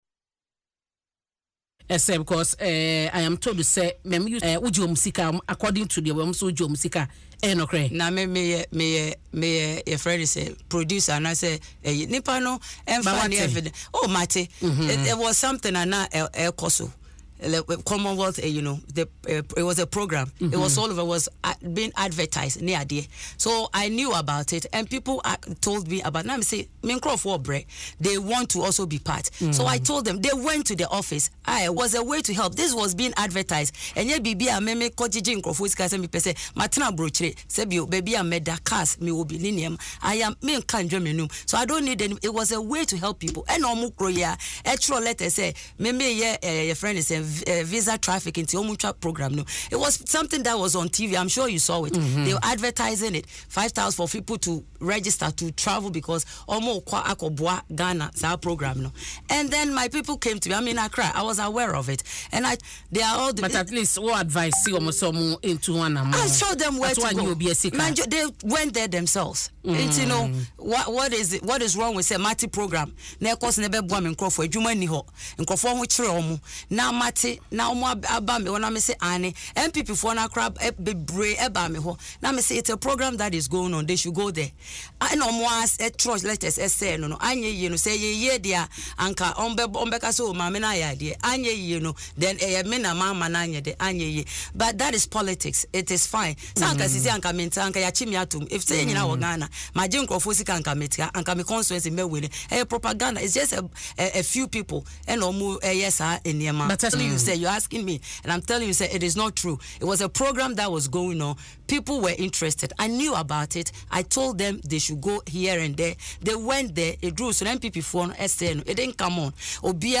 In an interview on Adom FM’s Dwaso Nsem, Madam Affo-Toffey admitted to introducing some youth to the program, emphasizing that it was genuine, although she did not provide many details.